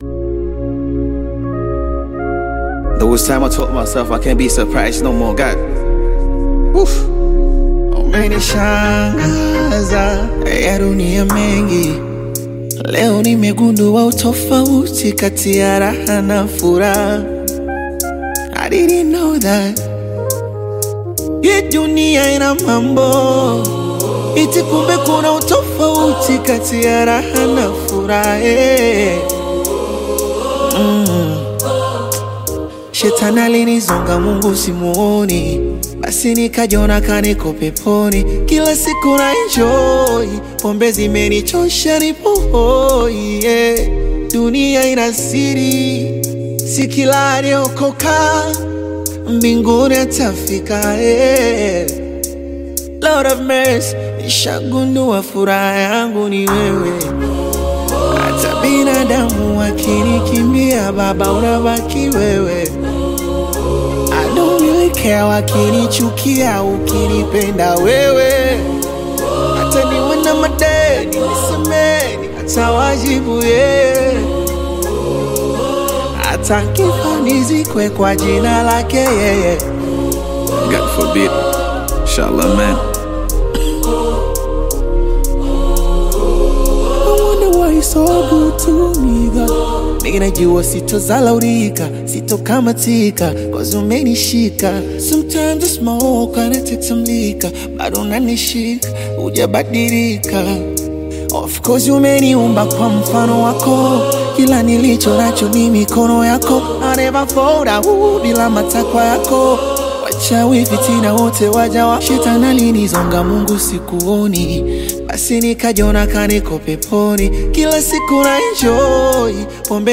deeply introspective Bongo Flava single
Blending emotive hooks with reflective verses
Genre: Bongo Flava